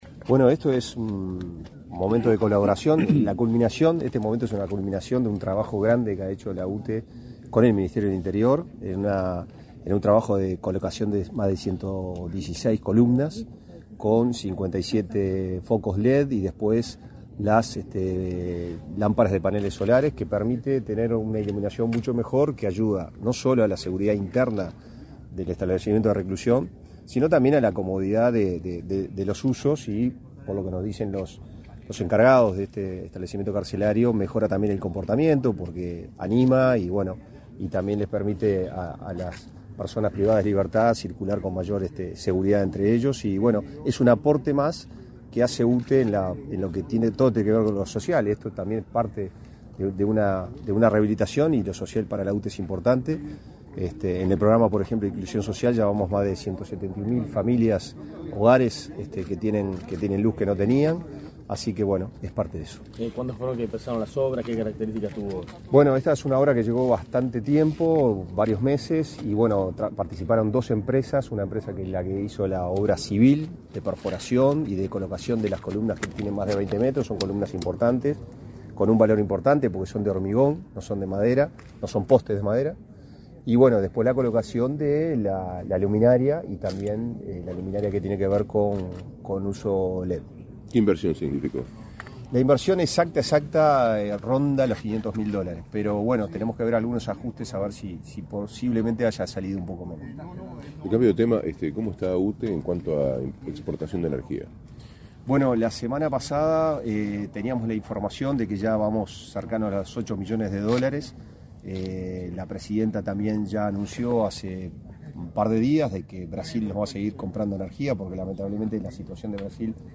Declaraciones a la prensa del vicepresidente de UTE, Pablo Ferrari
Declaraciones a la prensa del vicepresidente de UTE, Pablo Ferrari 29/05/2024 Compartir Facebook X Copiar enlace WhatsApp LinkedIn Este 29 de mayo, el Ministerio del Interior inauguró red lumínica en la Unidad n.°6 de Punta de Rieles. Tras el evento, el vicepresidente de UTE, Pablo Ferrari, realizó declaraciones a la prensa.